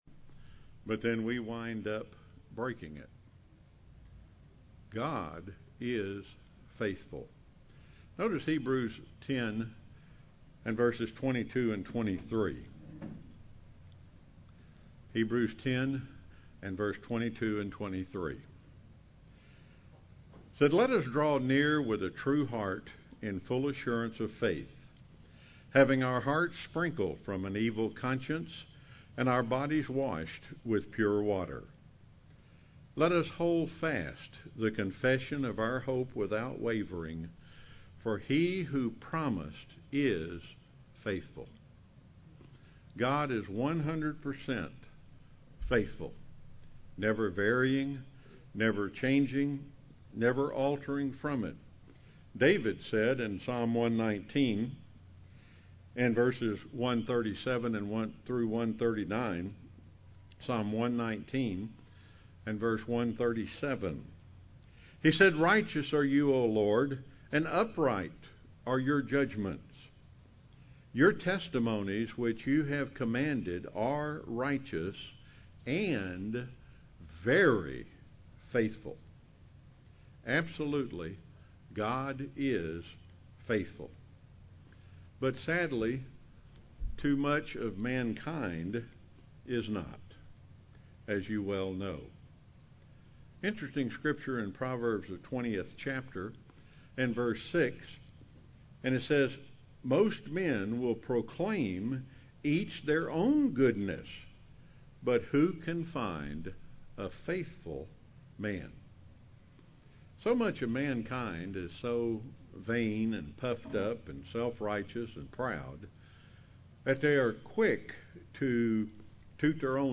Sermons
Given in Rome, GA